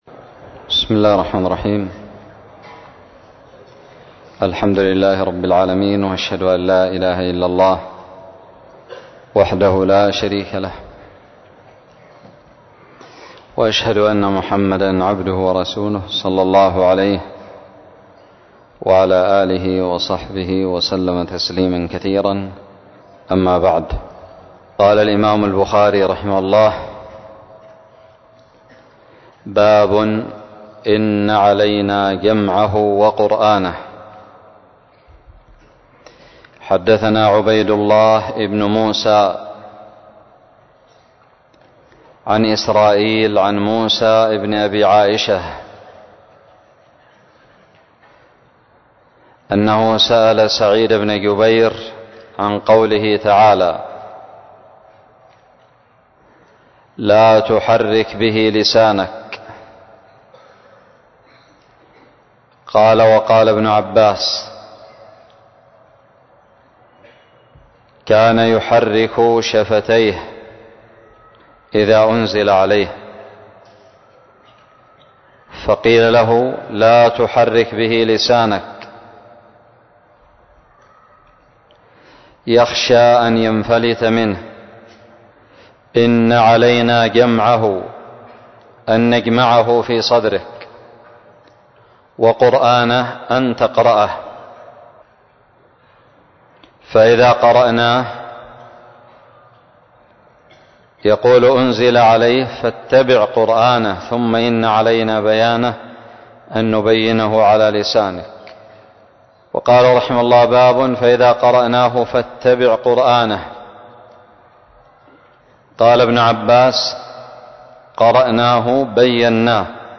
شرح صحيح الإمام البخاري- متجدد
ألقيت بدار الحديث السلفية للعلوم الشرعية بالضالع